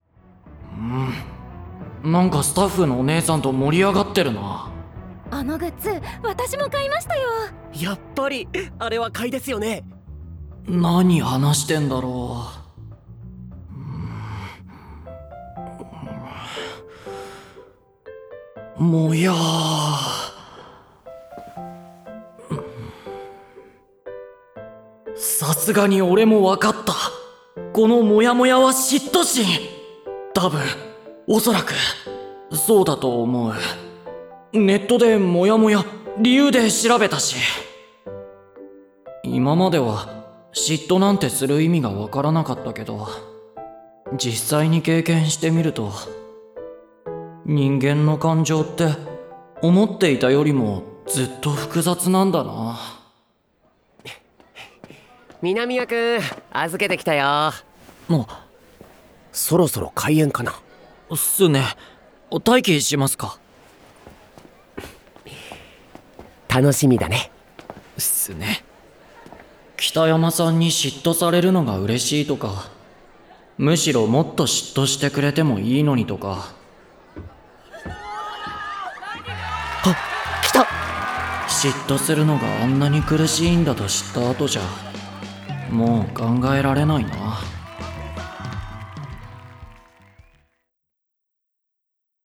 ドラマCD「北山くんと南谷くん2」
出演：広瀬裕也, 西山宏太朗